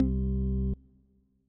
ORG Organ C1.wav